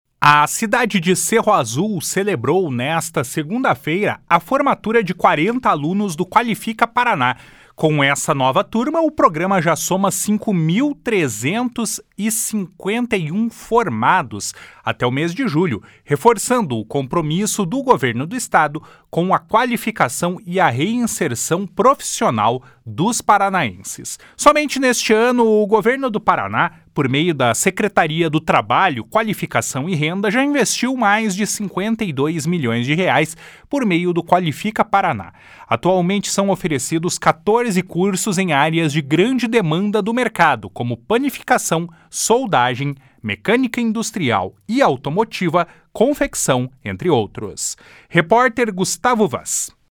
Atualmente, são oferecidos 14 cursos em áreas de grande demanda do mercado, como Panificação, Soldagem, Mecânica Industrial e Automotiva, Confecção, entre outros. (Repórter